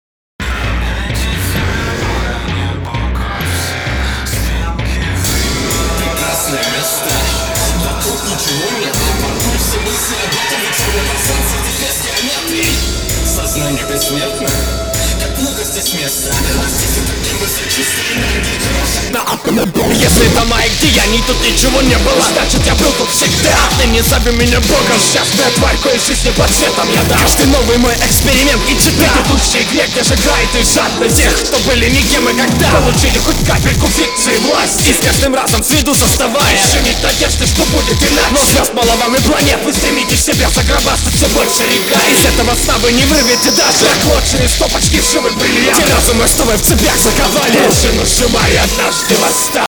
Переборщил как-то с экспериментальностью. Малослушабельно, и флоу, ритмического рисунка совсем нет как такового, ты не "плывёшь" по биту, а как-то обрывисто на него кричишь.